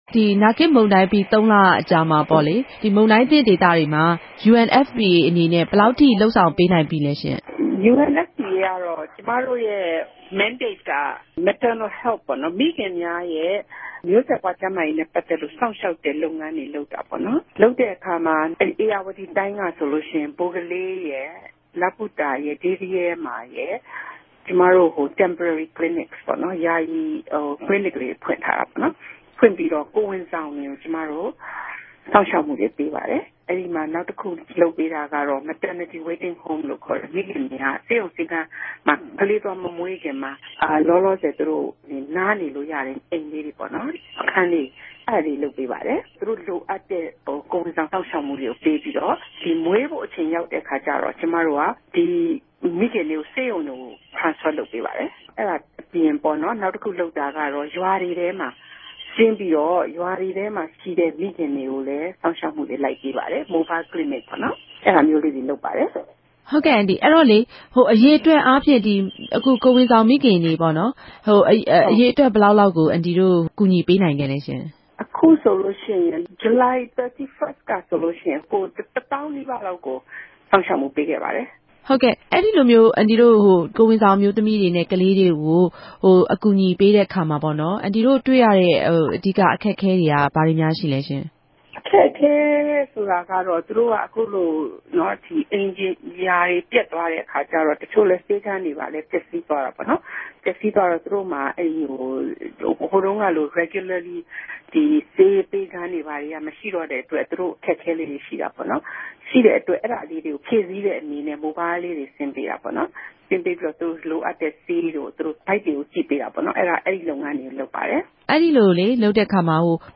ဆက်သြယ် မေးူမန်းခဵက်။